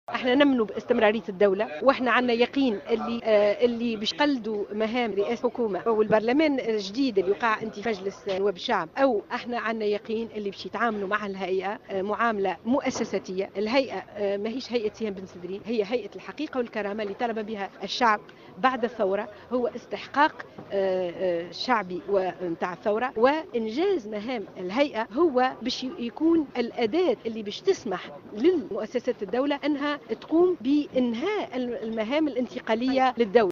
وأكدت بن سدرين في تصريح لجوهرة أف أم اليوم الاربعاء أهمية دور هيئة الحقيقة والكرامة في تأمين المرور من فترة انتقالية إلى نظام ديمقراطي ، نافية أن تكون غاية الهيئة تصفية حسابات شخصية من باب إيمان الهيئة بمبدأ استمرارية الدولة.